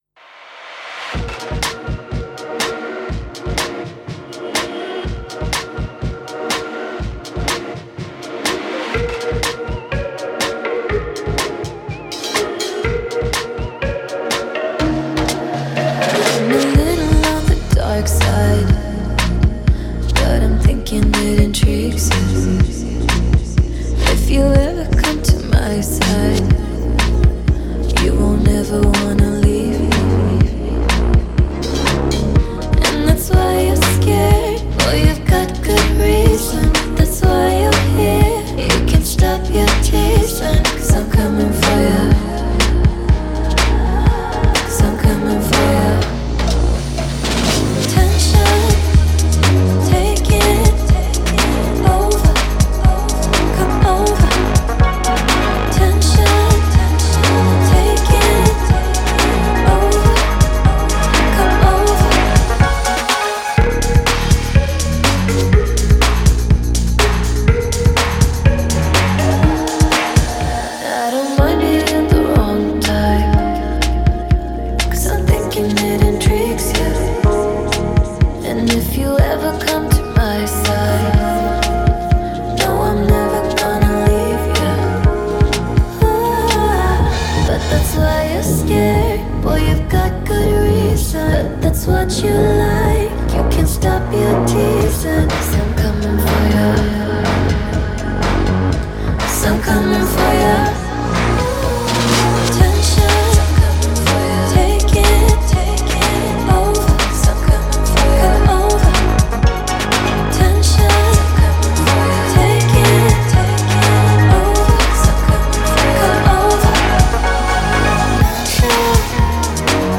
Genre: Electronic Dance